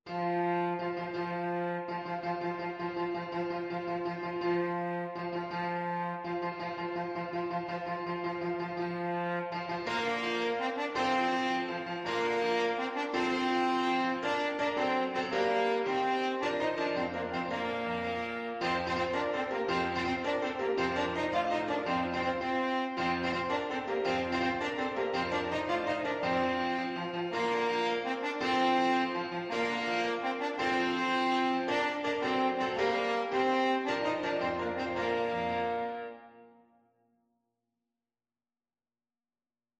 French Horn
Bb major (Sounding Pitch) F major (French Horn in F) (View more Bb major Music for French Horn )
6/8 (View more 6/8 Music)
With energy .=c.110
Classical (View more Classical French Horn Music)